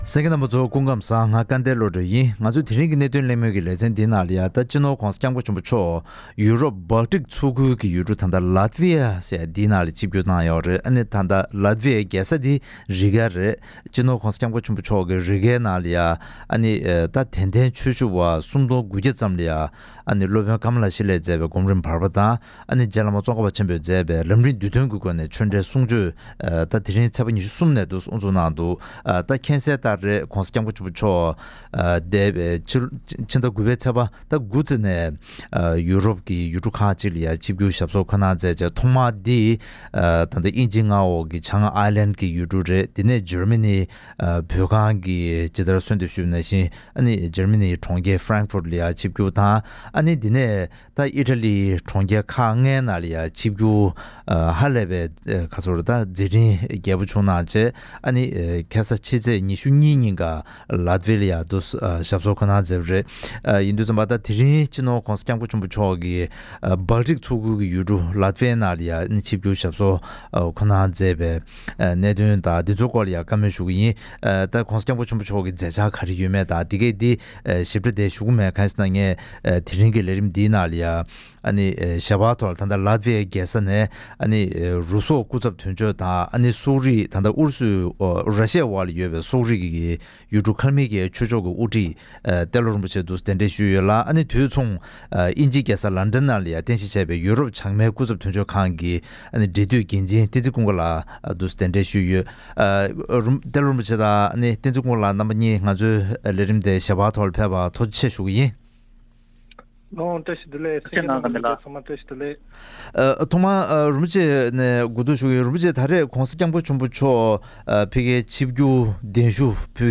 ༄༅། །ཐེངས་འདིའི་གནད་དོན་གླེང་མོལ་གྱི་ལེ་ཚན་ནང་།